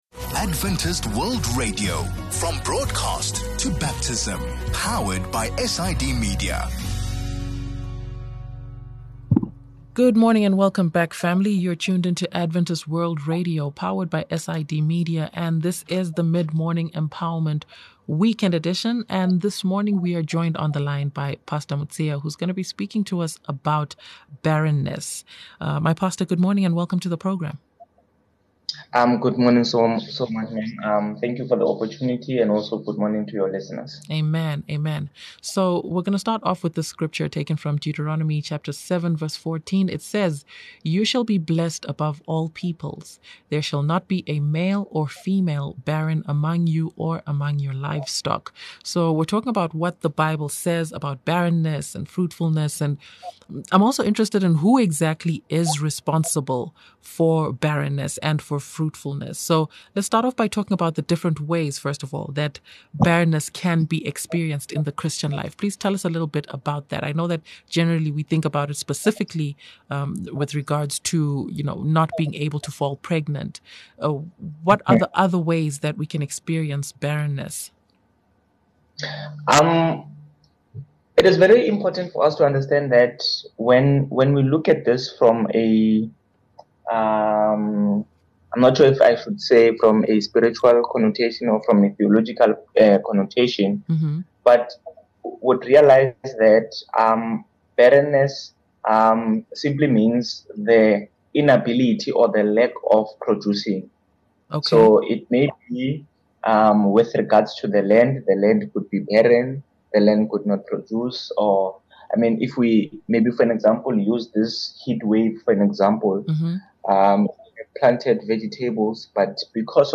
A candid conversation about what the Bible says about barrenness and fruitfulness, and who exactly is responsible for such.